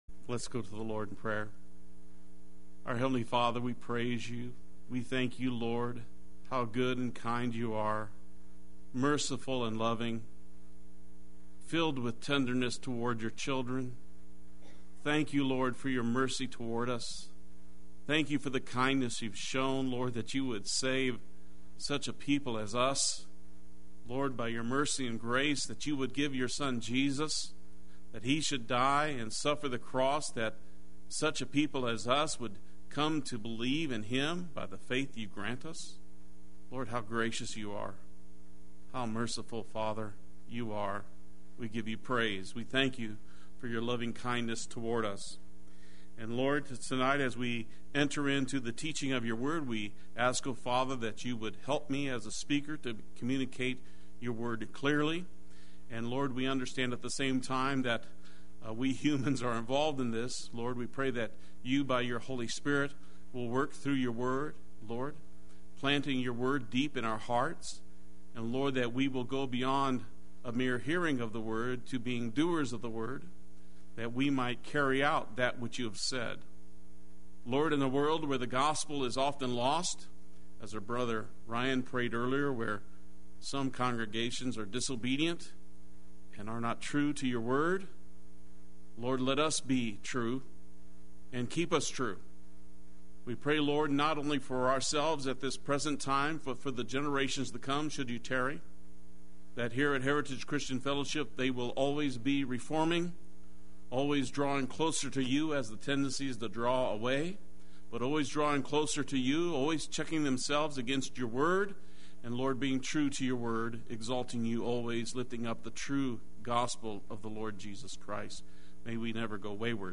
Play Sermon Get HCF Teaching Automatically.
The Truth of God Wednesday Worship